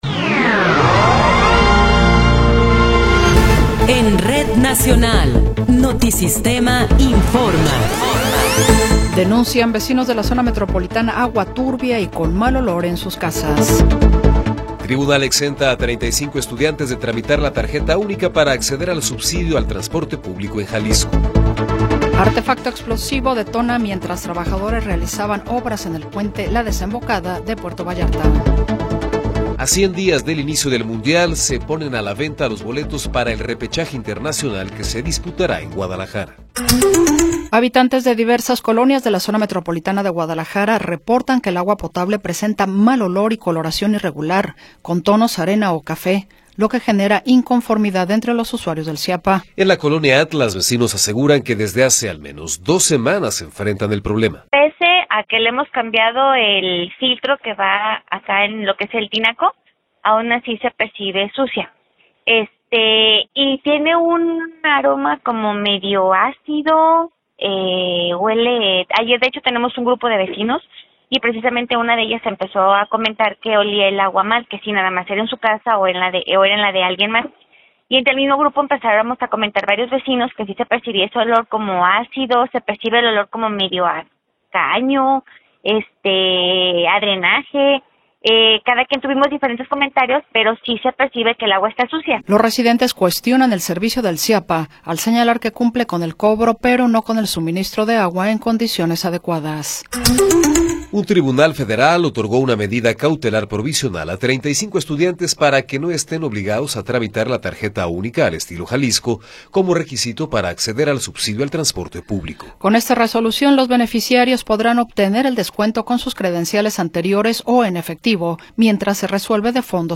Noticiero 14 hrs. – 3 de Marzo de 2026
Resumen informativo Notisistema, la mejor y más completa información cada hora en la hora.